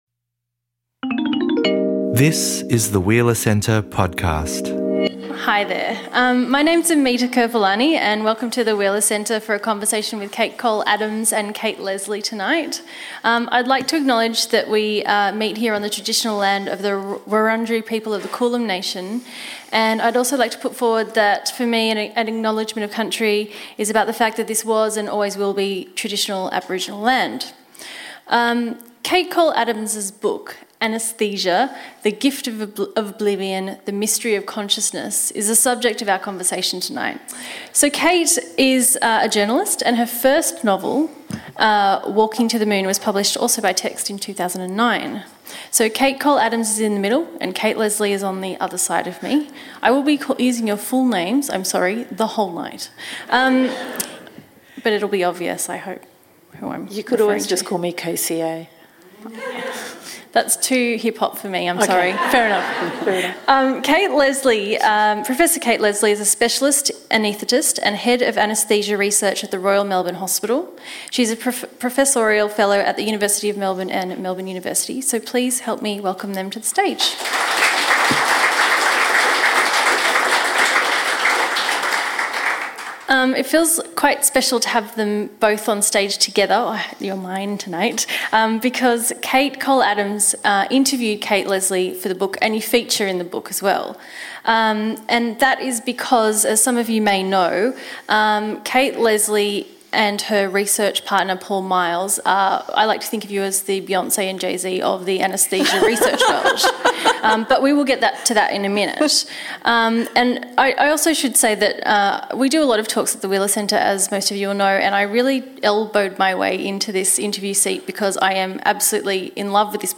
We look into these questions, and more, in this riveting discussion on memory, pain and administered oblivion.